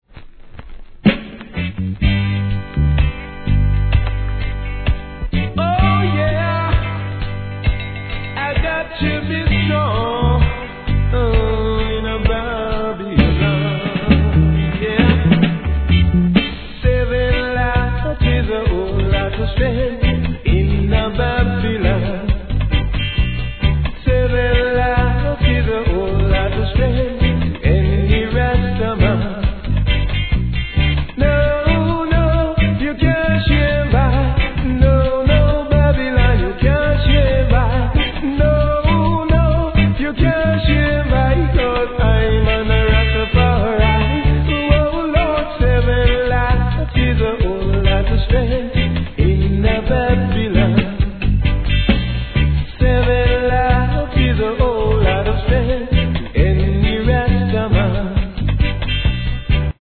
REGGAE
素晴らしいヴォーカルで聴かせるラスタ・ソング♪